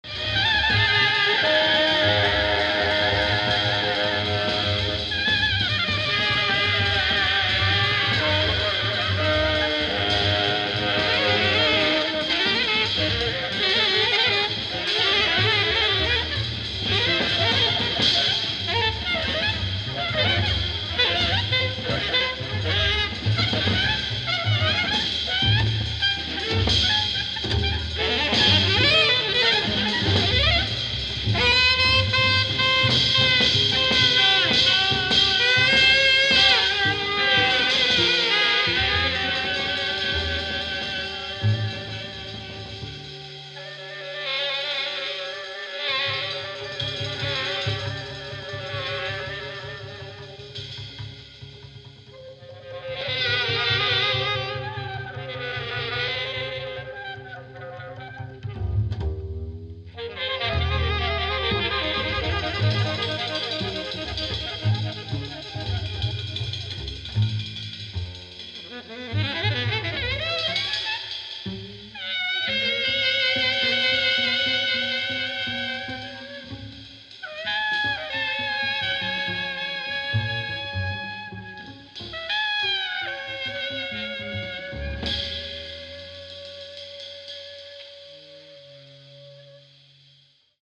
musical saw